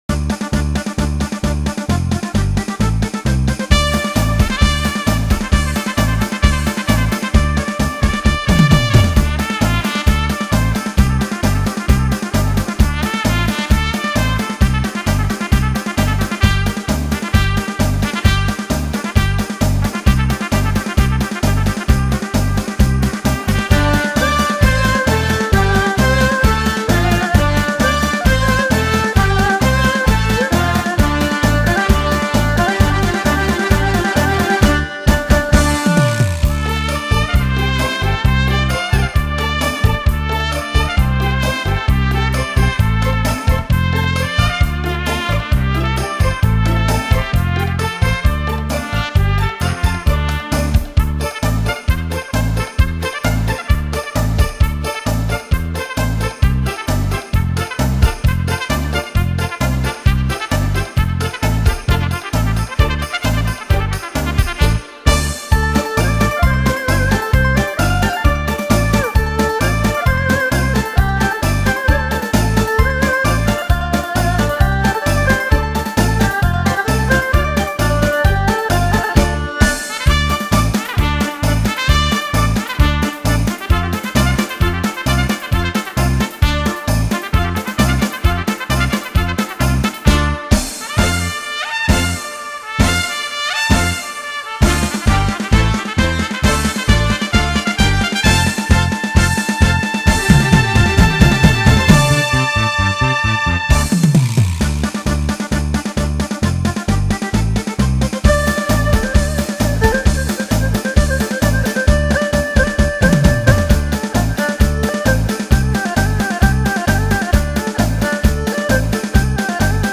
新世纪音乐
小号，俗称小喇叭，铜管乐器家族的一员，常负责旋律部分或高吭节奏的演奏，也是铜管乐器家族中音域最高的乐器。
小号音色强烈，明亮而锐利，极富光辉感，是铜管族中的高音乐器。